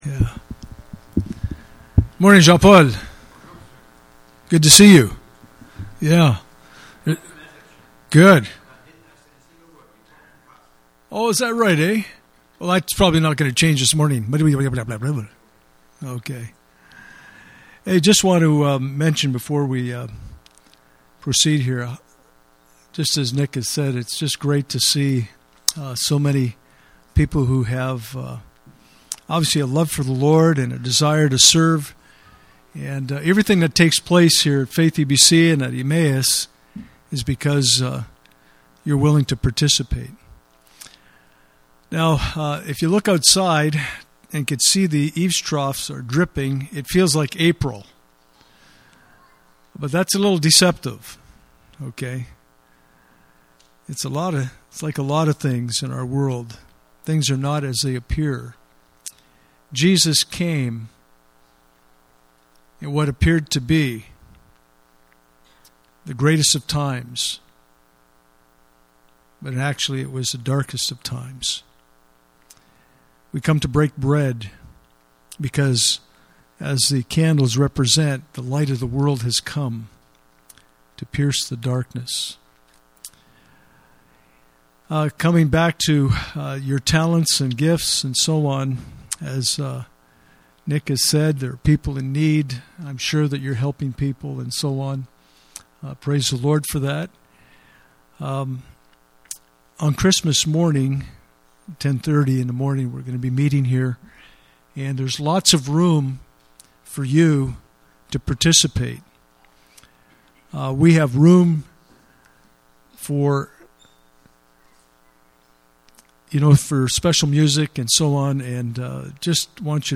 Communion Service
Service Type: Sunday Morning